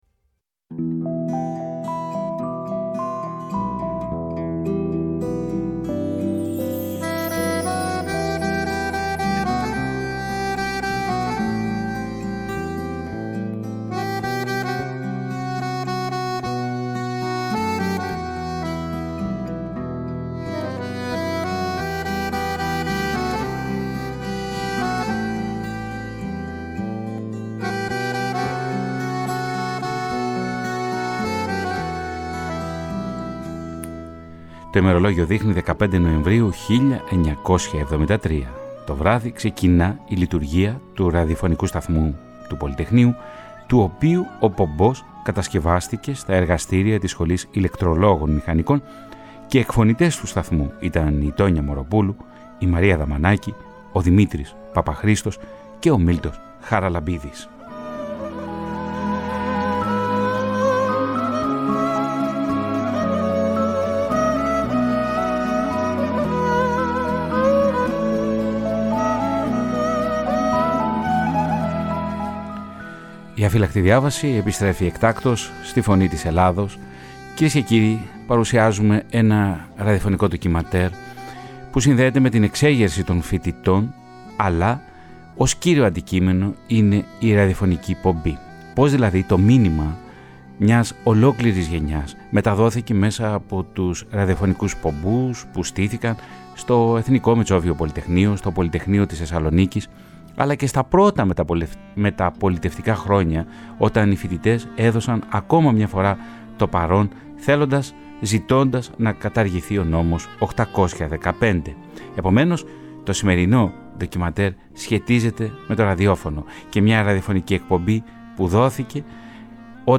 Η ‘Αφύλαχτη Διάβαση‘ επέστρεψε εκτάκτως την Παρασκευή 17 Νοεμβρίου, και παρουσίασε ένα συγκλονιστικό ραδιοφωνικό ντοκιμαντέρ με τίτλο Ο ΡΑΔΙΟΦΩΝΙΚΟΣ ΠΟΜΠΟΣ ΤΟΥ ΠΟΛΥΤΕΧΝΕΙΟΥ. Μέσα από πρωτότυπες μαρτυρίες, που ακούγονται για πρώτη φορά δημόσια, αποκαλύπτεται όλο το σχέδιο δημιουργίας του ραδιοφωνικού σταθμού του Πολυτεχνείου.